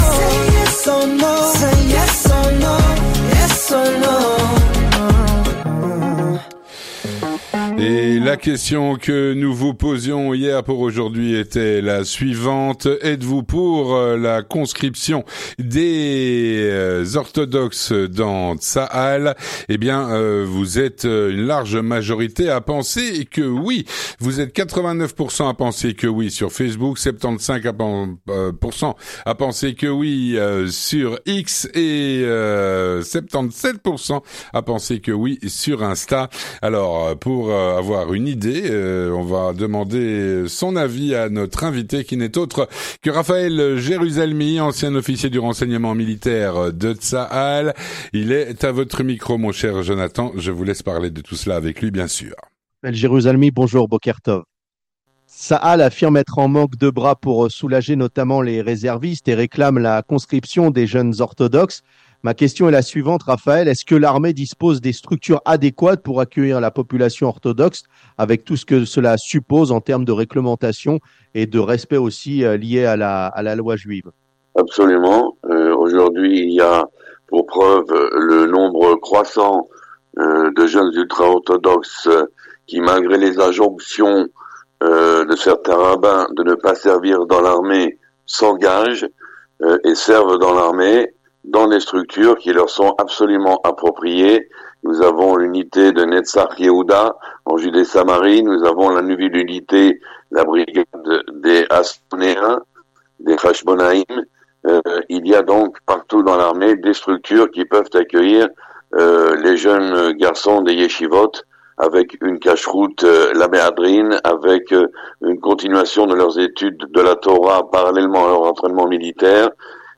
Raphaël Jerusalmy, ancien Officier du Renseignement Militaire de Tsahal, répond à la “Question Du Jour”.